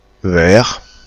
Ääntäminen
ÄäntäminenFrance (Aquitaine):
• IPA: [vɛːʁ̥]